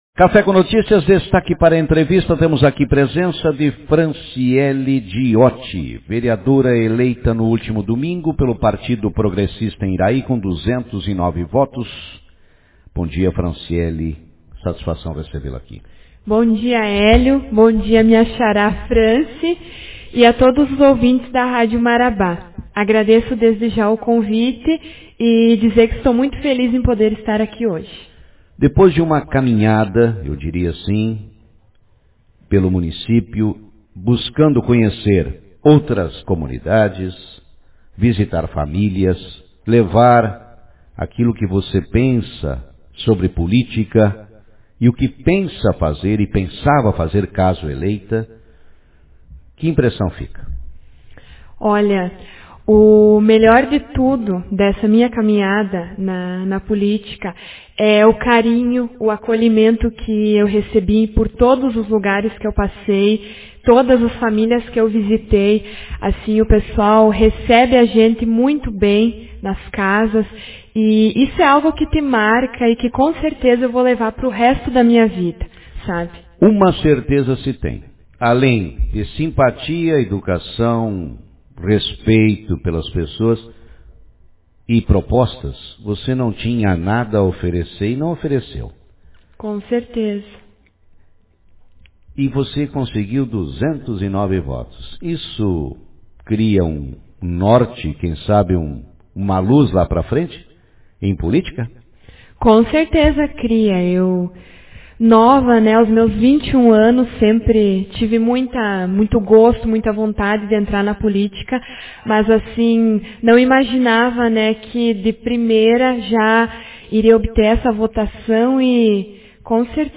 Franciele Diotti, vereadora eleita em Iraí aos 21 anos, fala no Jornal do Meio-Dia
entrevista